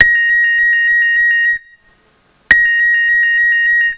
Tono_2 alternativo
Tono_2 alternativo.wav